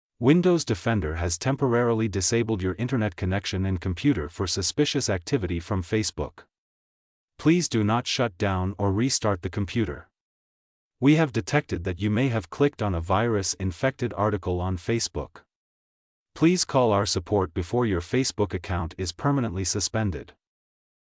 text-to-speech.mp3